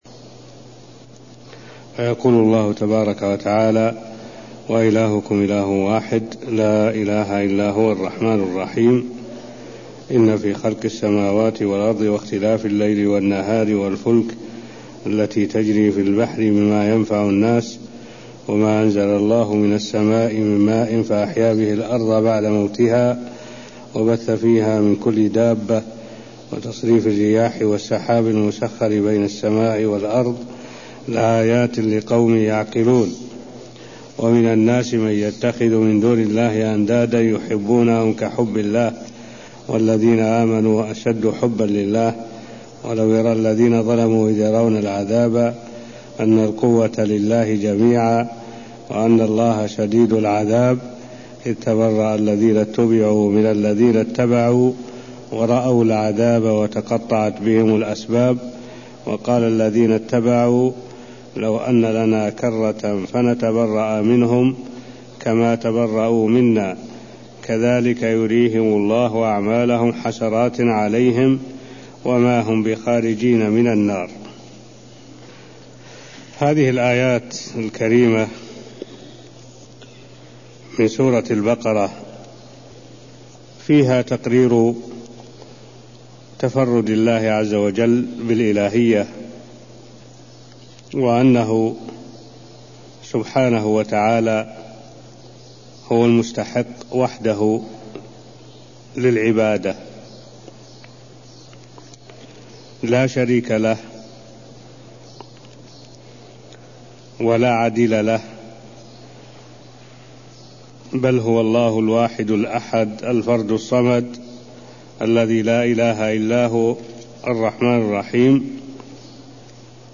المكان: المسجد النبوي الشيخ: معالي الشيخ الدكتور صالح بن عبد الله العبود معالي الشيخ الدكتور صالح بن عبد الله العبود تفسير الآيات163ـ165 من سورة البقرة (0083)